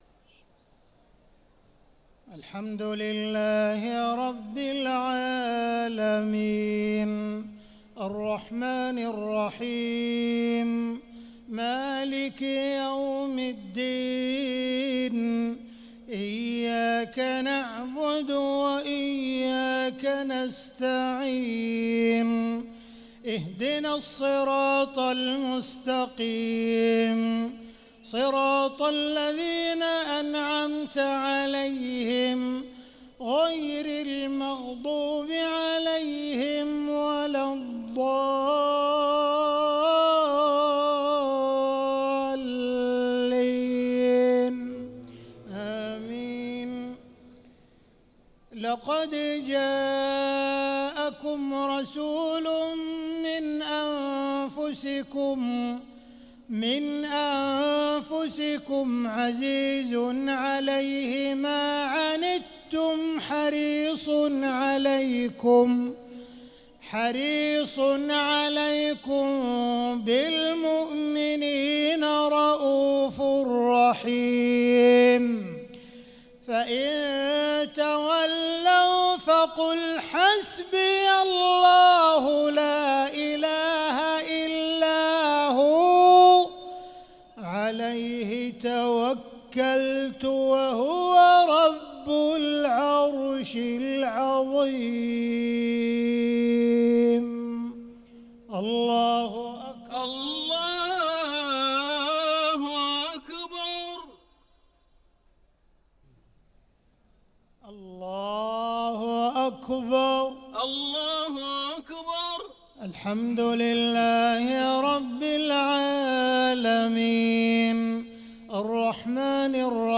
صلاة المغرب للقارئ عبدالرحمن السديس 26 رمضان 1445 هـ
تِلَاوَات الْحَرَمَيْن .